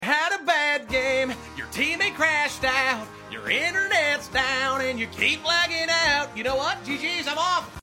Remix
Instrumental I Sang Over